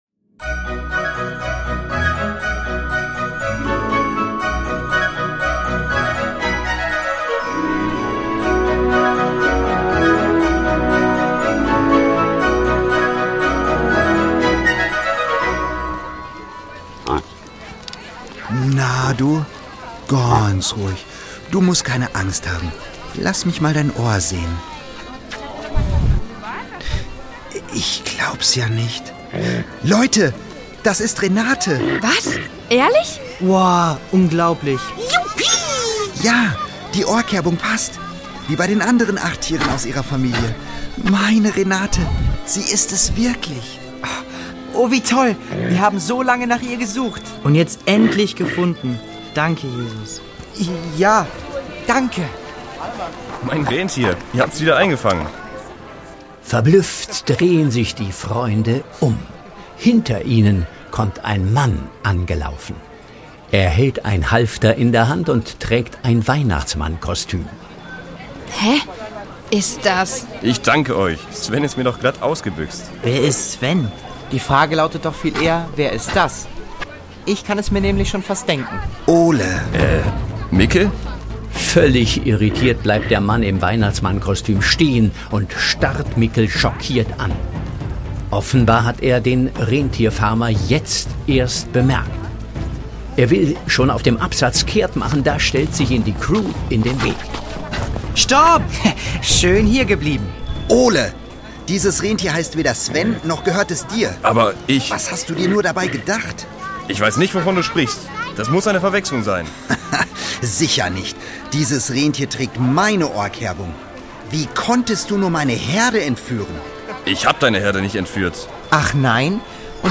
Die Doppeldecker Crew | Hörspiel für Kinder (Hörbuch)